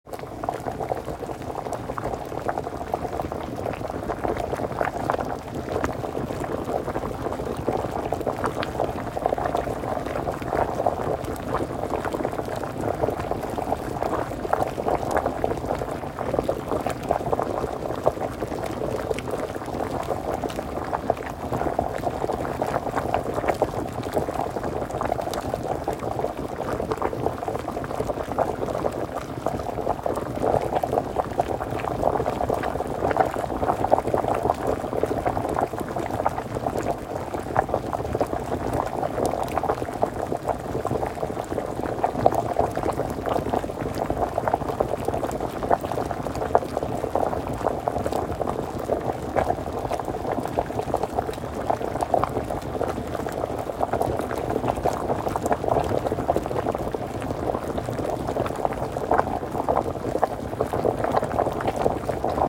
Boiling potatoes loop
I need a looped sound of boiling potatoes.